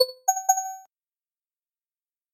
Звук успешной оплаты в приложении